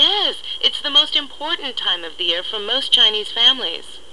英語發音 English Pronunciation
(加連線者為連音，加網底者不需唸出聲或音很弱。)